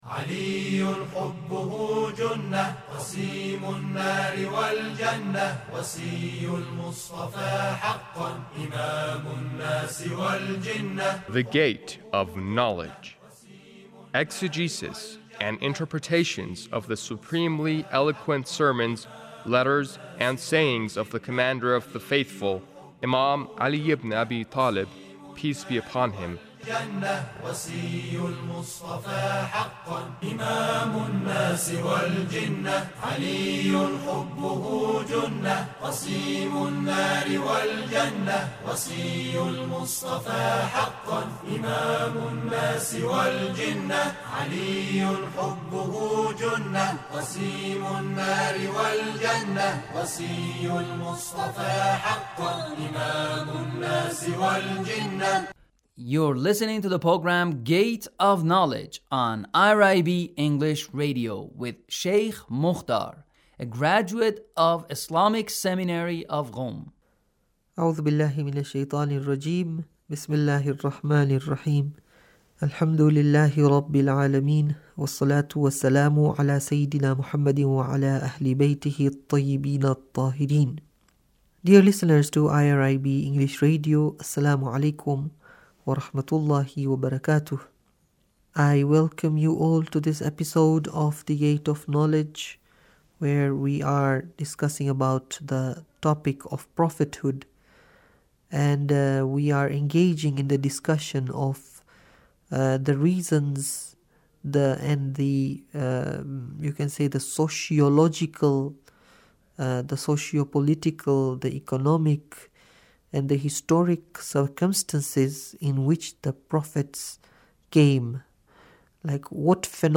Sermon 1 - Prophethood 11